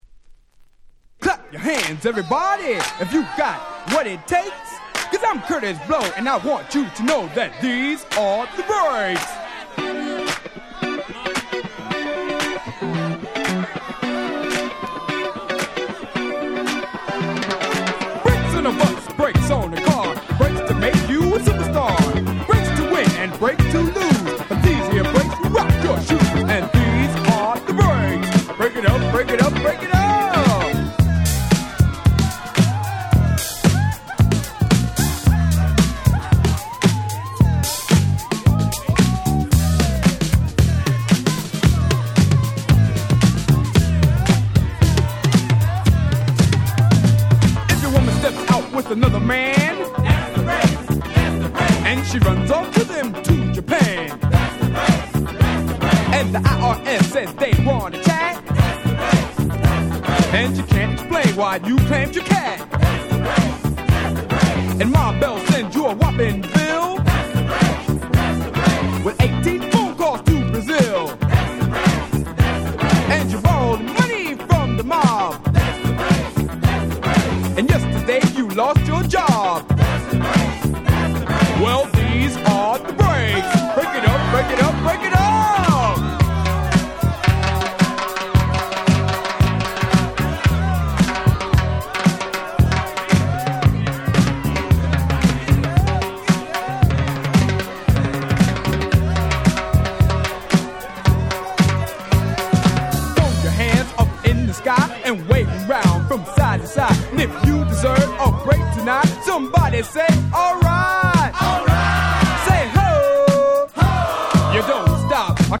80' Old School Hip Hop Super Classics !!